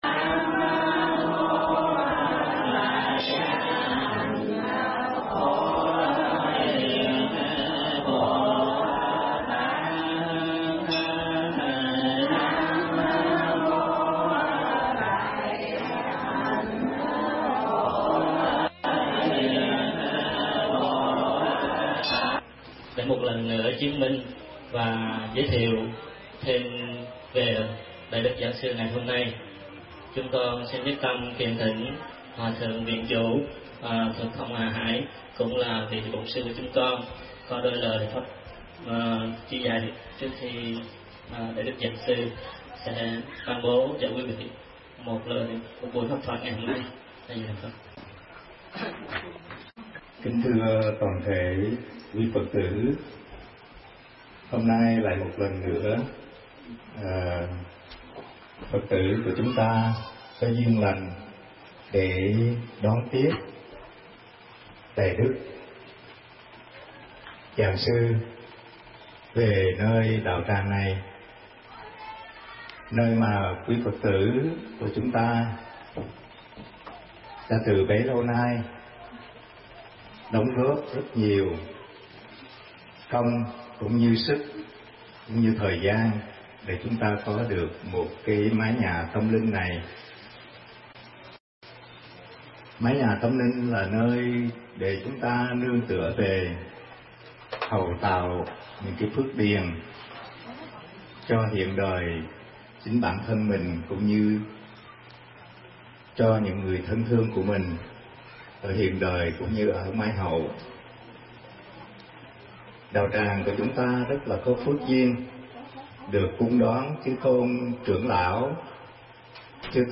Mp3 Thuyết Giảng Phiền Não Từ Đâu Sanh Phần 1